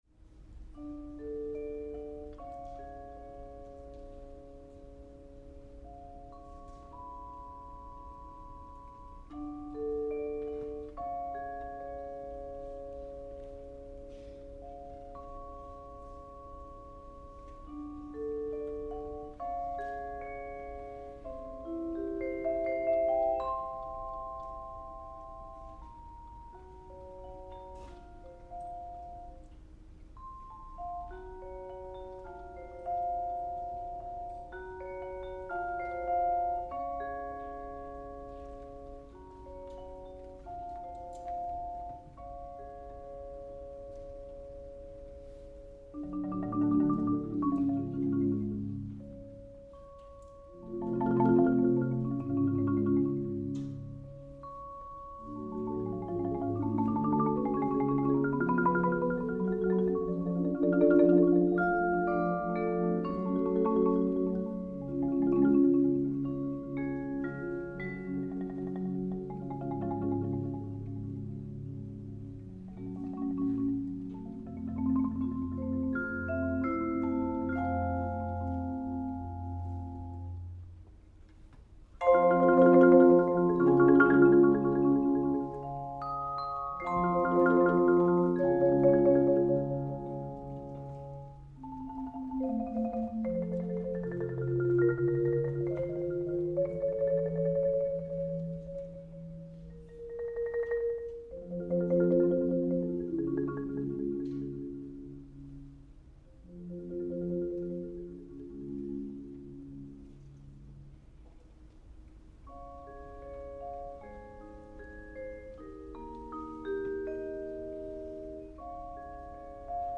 Genre: Percussion Ensemble
# of Players: 12
Bells/Chimes
Xylophone
Marimba 1 (4 octave)
Bass Marimba
Timpani
Percussion 1 (Suspended Cymbal, Snare Drum, Ride Cymbal)
Percussion 2 (Wind Chimes, Bass Drum)
Percussion 4 (Triangle, Tambourine, Temple Blocks)